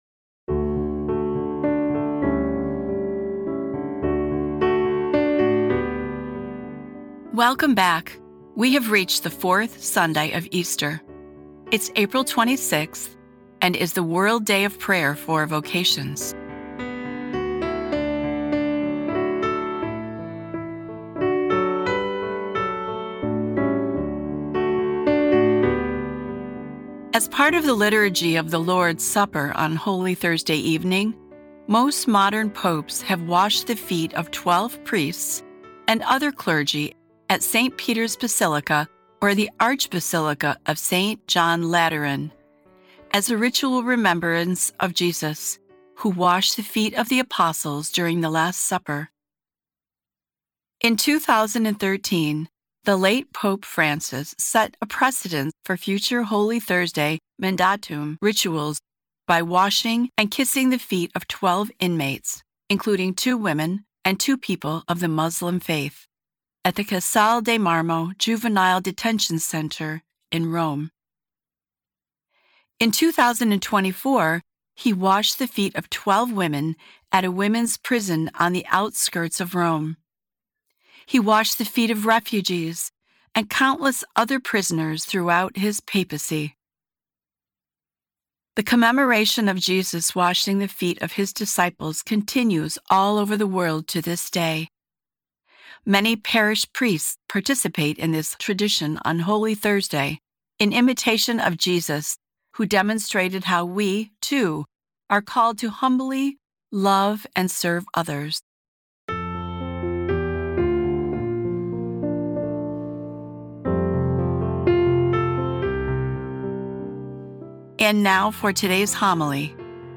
Today's episode of Sundays with Bishop Ken is a reading from The Little White Book: Easter 2026.